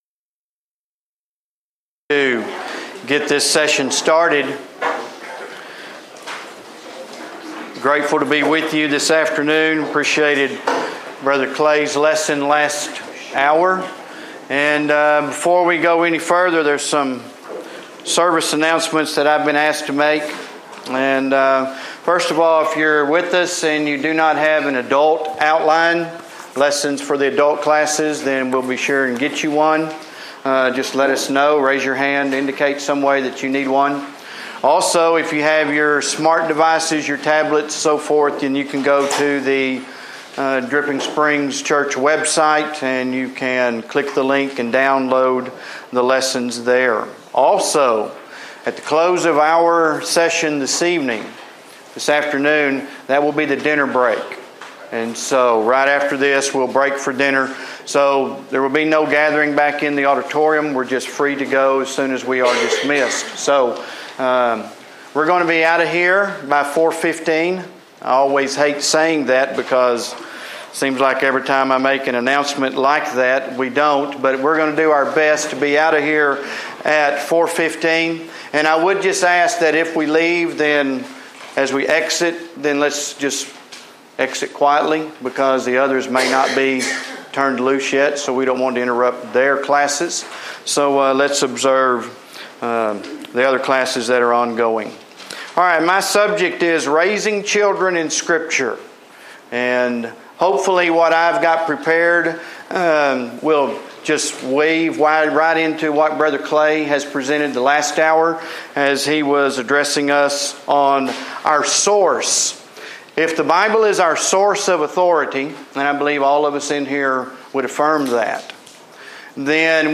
Event: Discipleship U 2016
lecture